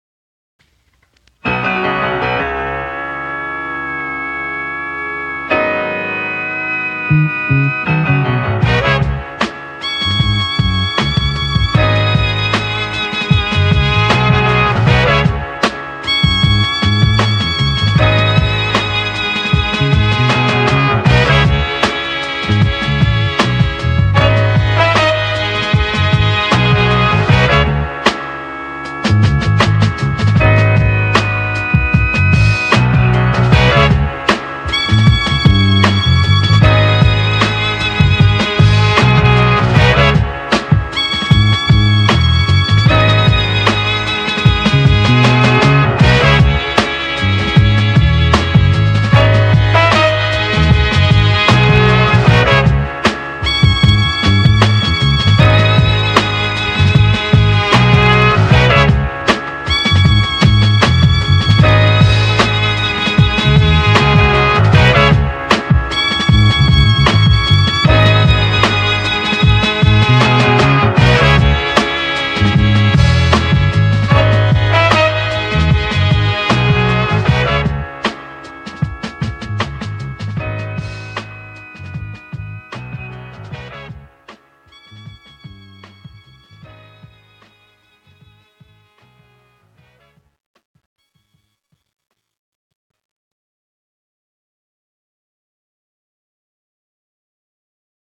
royalty-free sample pack